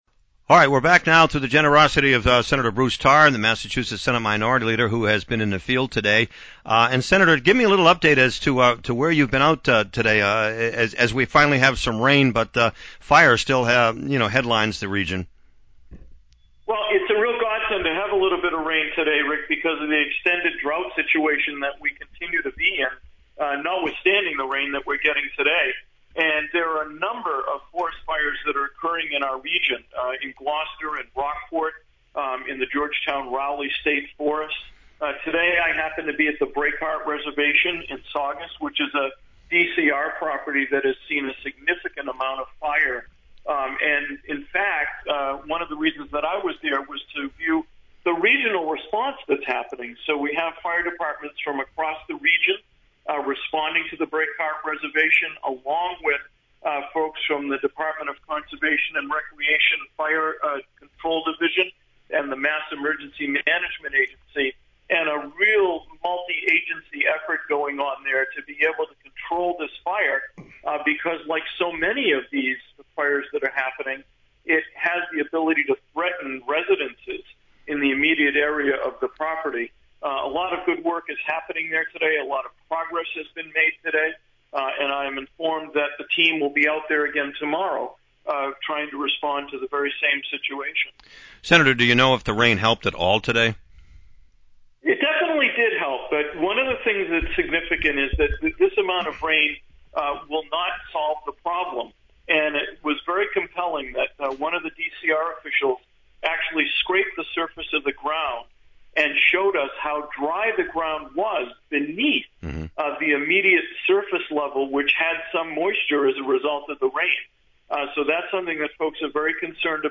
State Senator Bruce Tarr after visiting with fire officials in Saugus